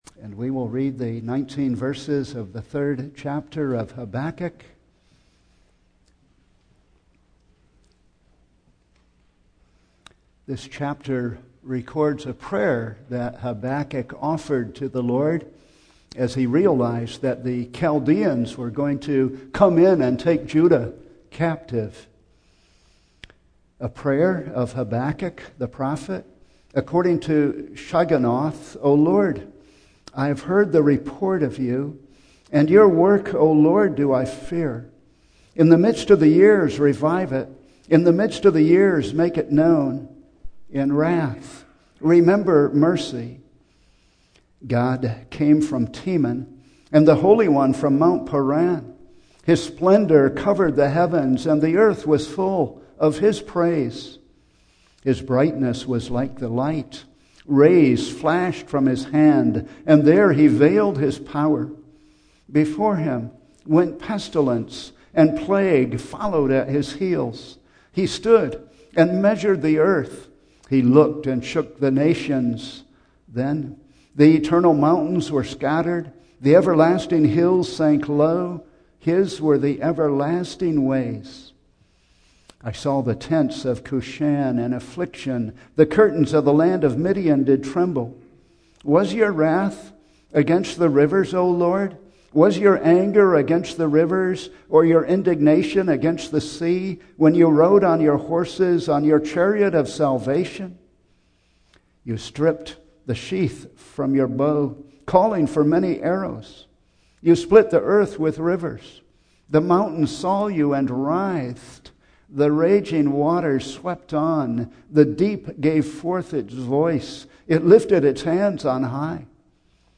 Single Sermons - Lynwood United Reformed Church - Page 4